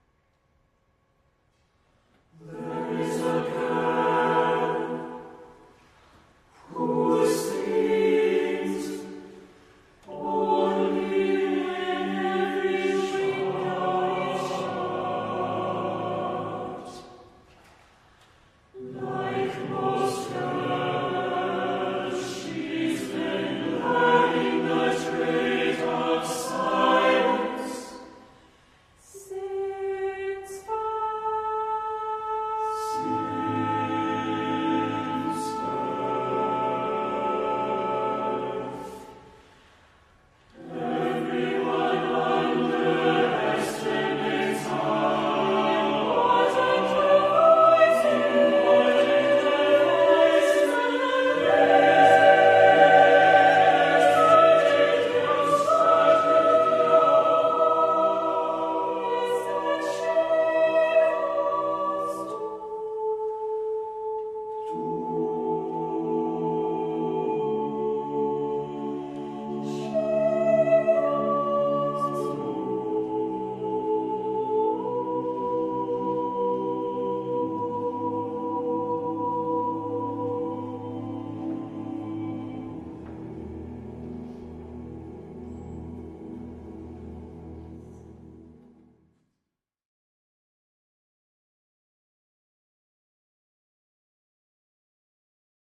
SATB div. choir a cappella